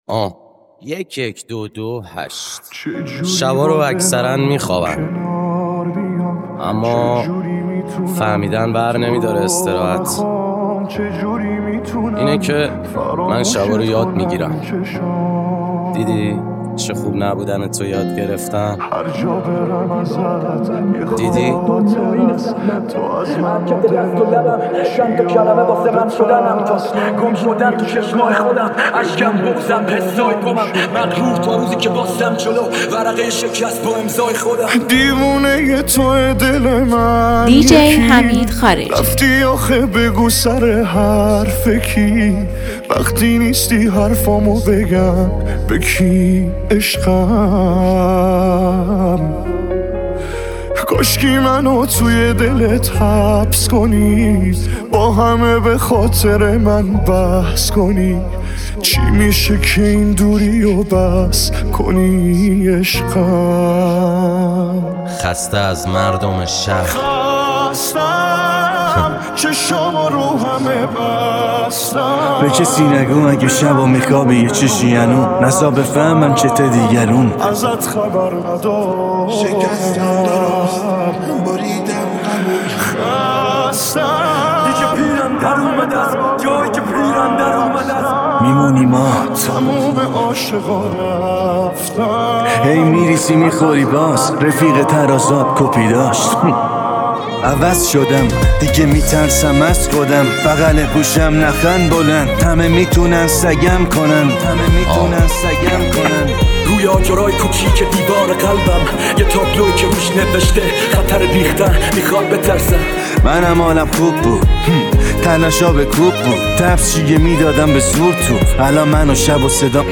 ریمیکس رپ